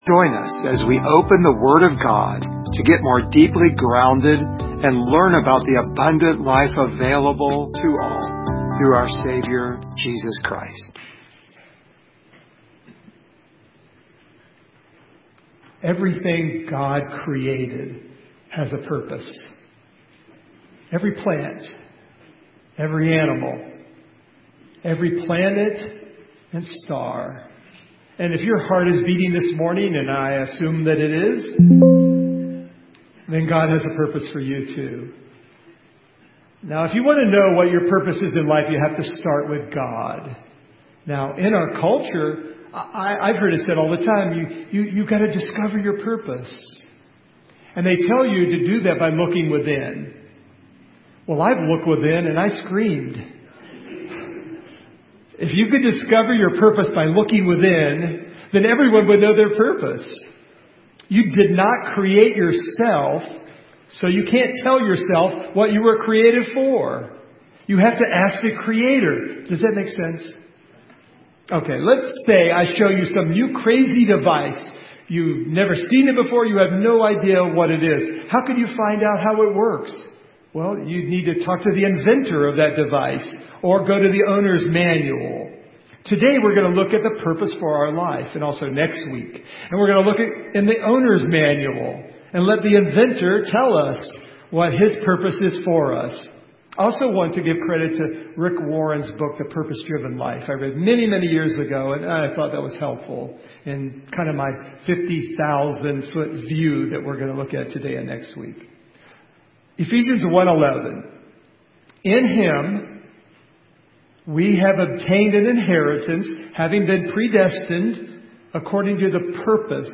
Service Type: Sunday Morning
Topics: Christian Life , God share this sermon « What Would Santa Say About Christmas?